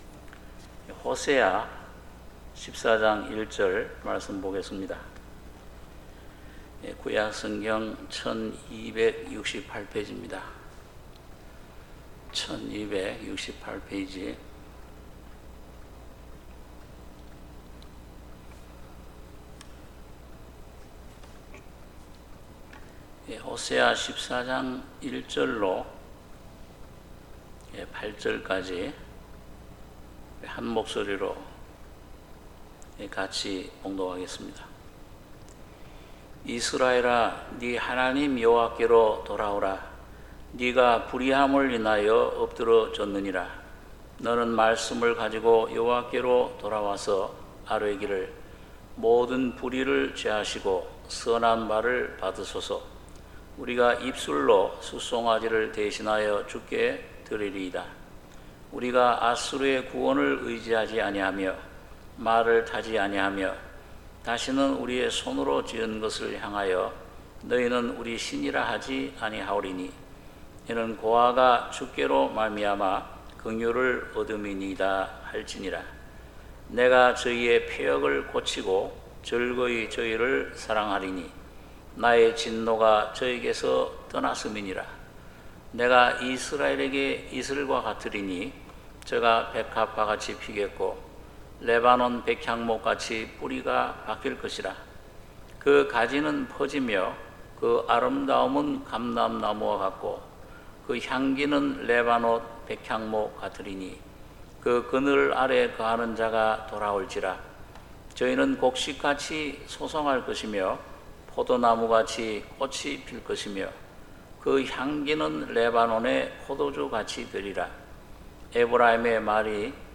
수요예배 호세아 14:1-8